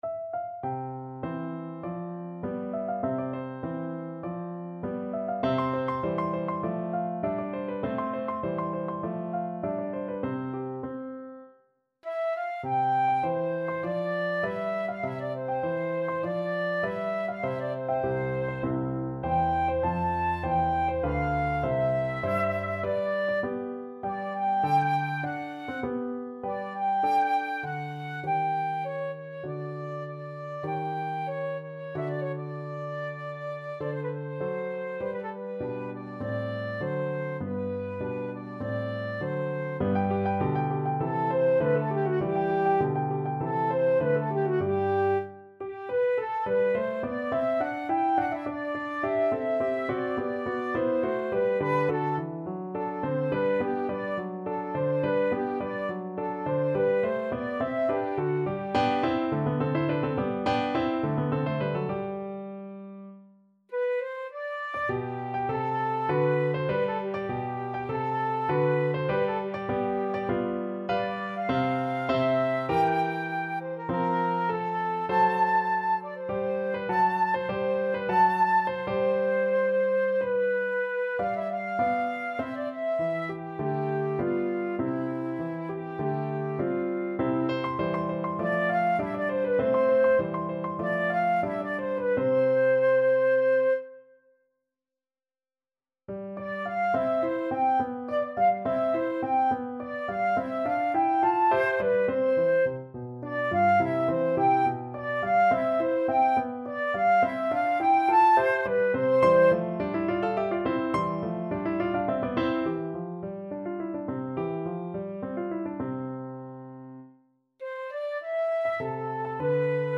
Flute version
2/4 (View more 2/4 Music)
~ = 50 Larghetto
Classical (View more Classical Flute Music)